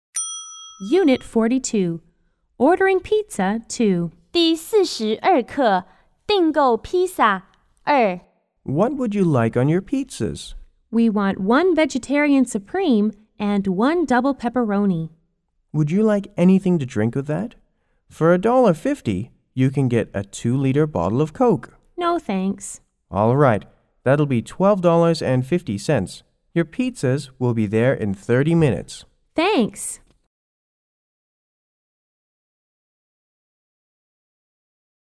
W= Worker C= Caller